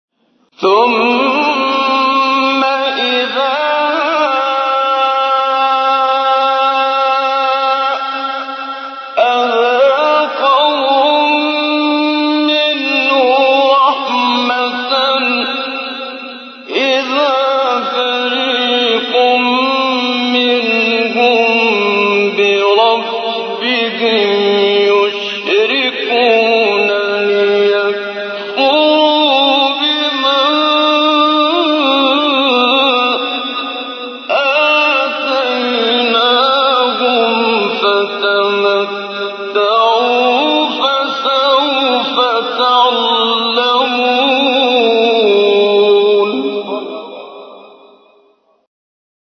سوره : روم آیه: 33-34 استاد : محمد صدیق منشاوی مقام : رست قبلی بعدی